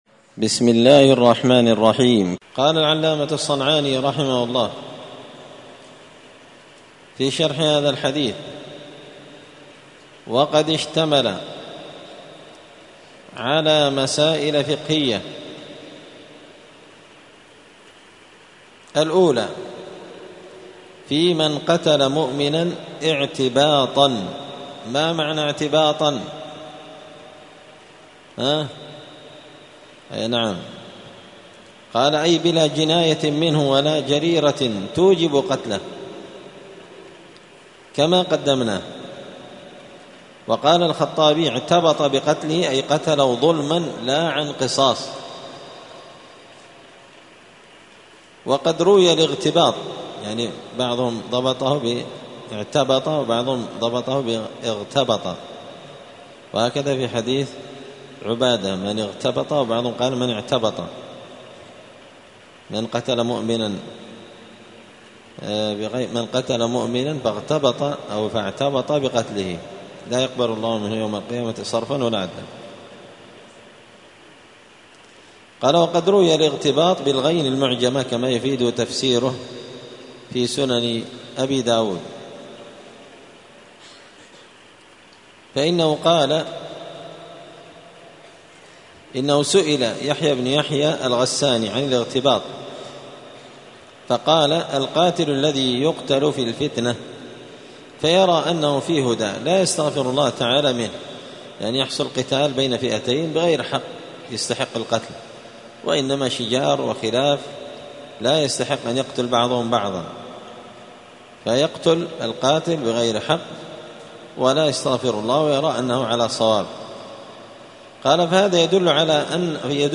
*الدرس التاسع عشر (19) {باب الديات الأنف إذا أوعب جدعه}*